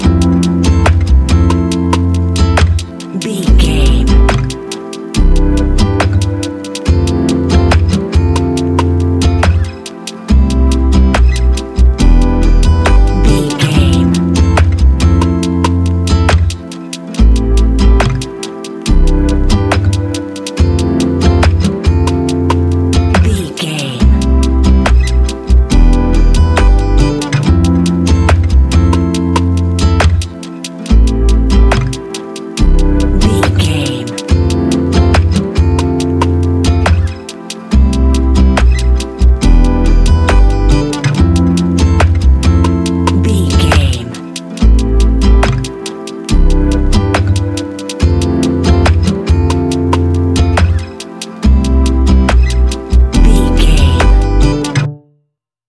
Ionian/Major
laid back
Lounge
sparse
new age
chilled electronica
ambient